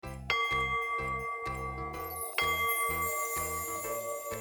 cuckoo-clock-02.wav